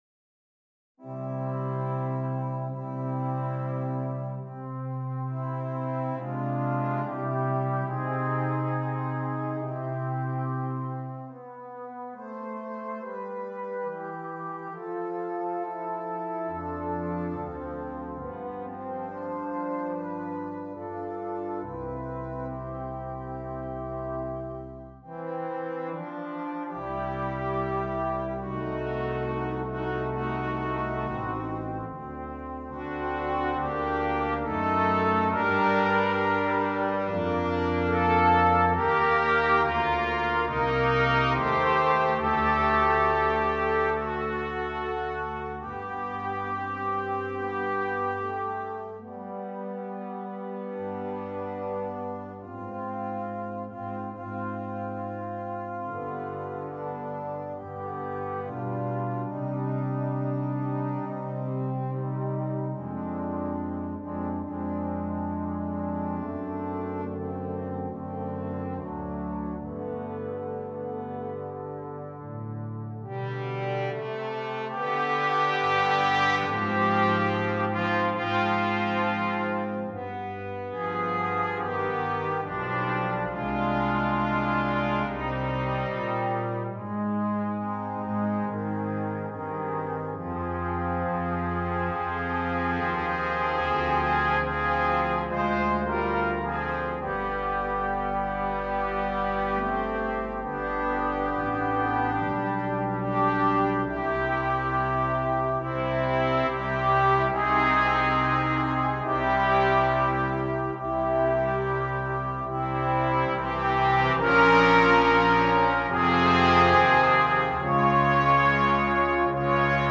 Brass Choir (2.2.2.0.1)